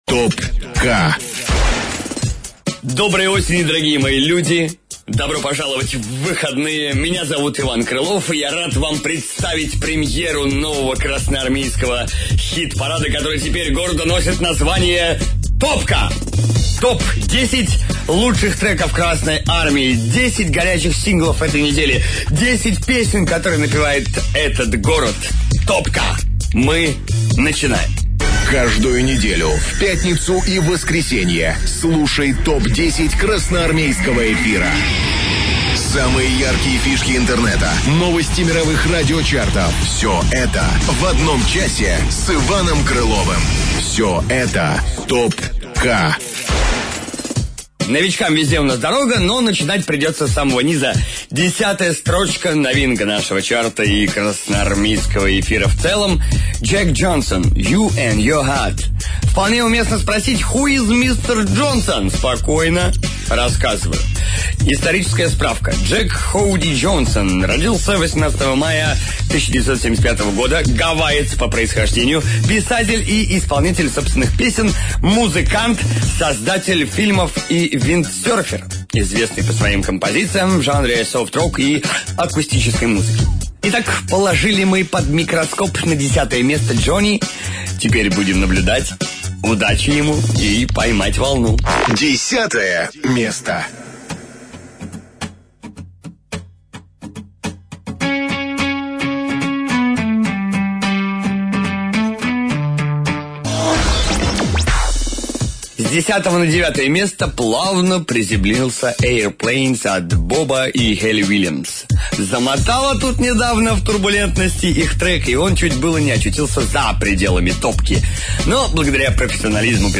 "Топка" на "Красной Армии". Запись эфира.